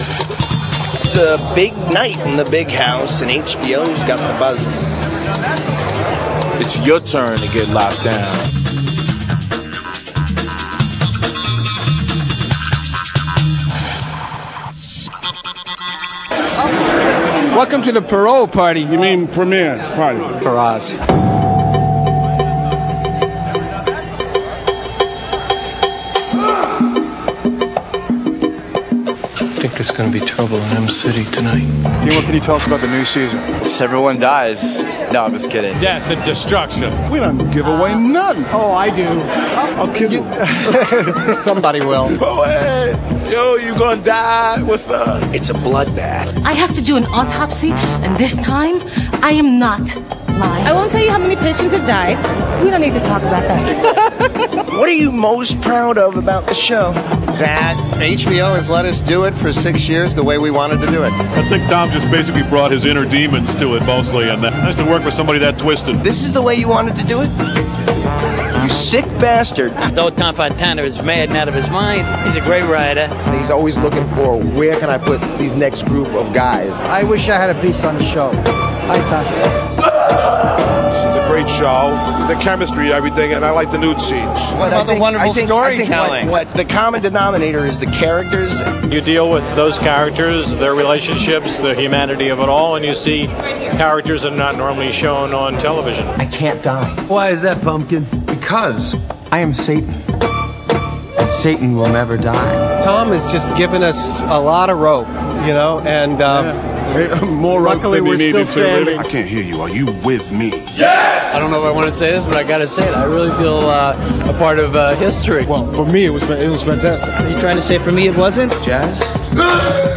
I found some OZ interview video- files in  "Twisted Sisterhood" (OZ Related Yahoo Group).
Here,This is the Oz's S6 premiere party with interviews video file.